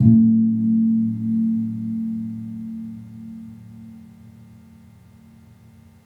Gong-A1-p.wav